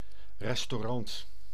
Ääntäminen
Synonyymit restauratie eethuis Ääntäminen : IPA: [rɛs.tɔ.'rɑnt] Haettu sana löytyi näillä lähdekielillä: hollanti Käännös Ääninäyte Substantiivit 1. restaurant UK Suku: n .